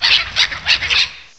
cry_not_vullaby.aif